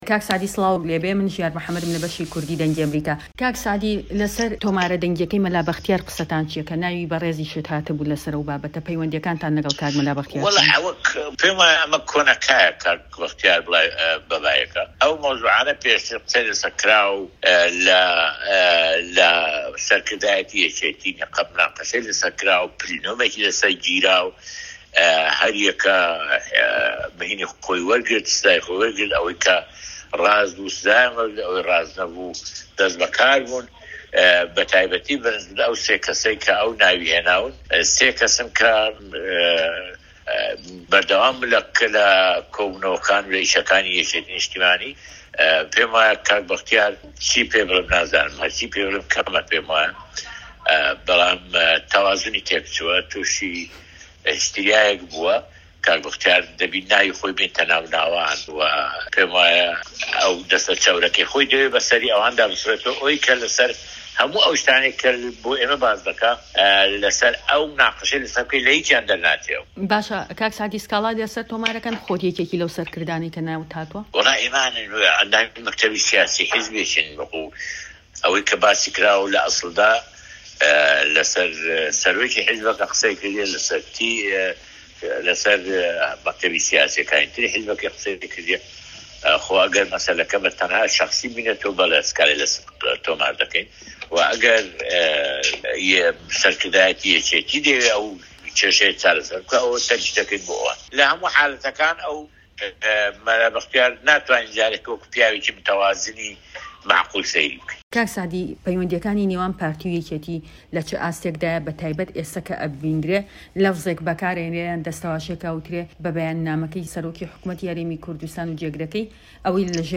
وتووێژی سەعدی ئەحمەد پیرە